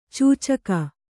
♪ cūcaka